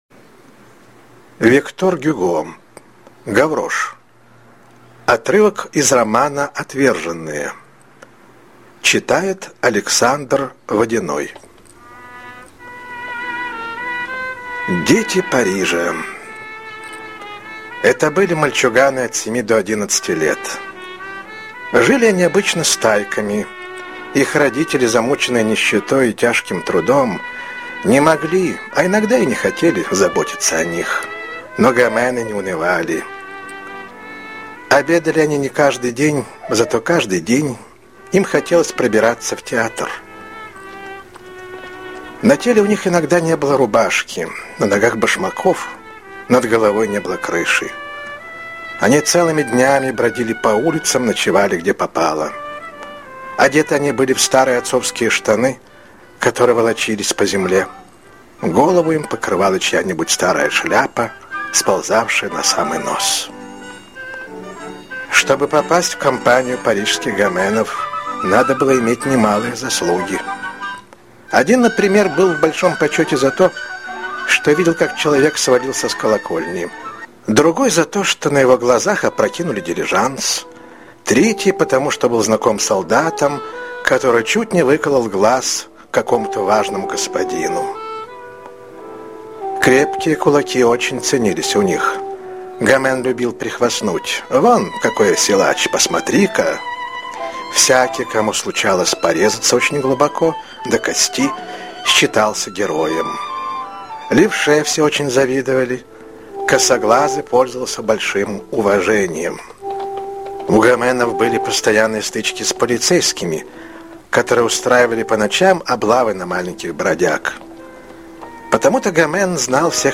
Гаврош - аудио роман Гюго - слушать онлайн